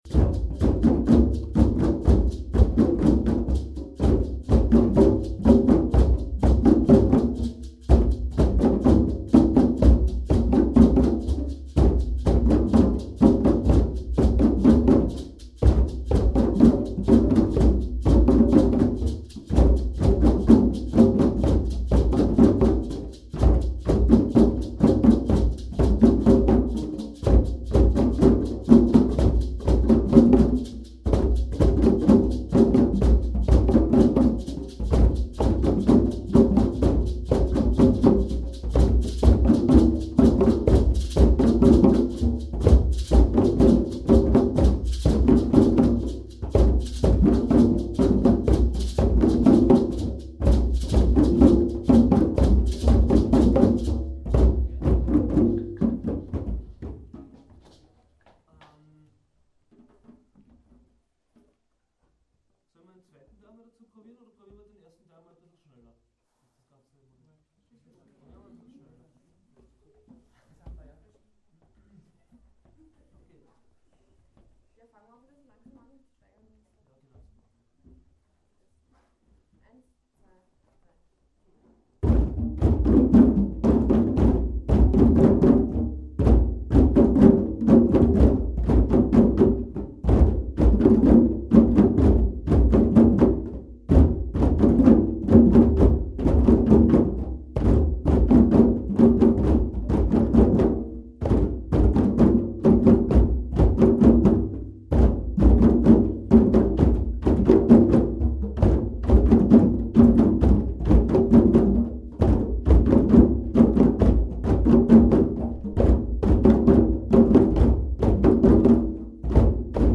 Tonbeispiel vom workshop (mp3-Datei Downloaden)
Mit großem Geschick und Einfühlungsvermögen gelang es den beiden Referenten, die Kursteilnehmer dazu zu bringen, bis zum Ende der Veranstaltung schon sehr heiße Rhythmen auf den Djemben zu erzeugen.
Homepage_Trommelworkshop.mp3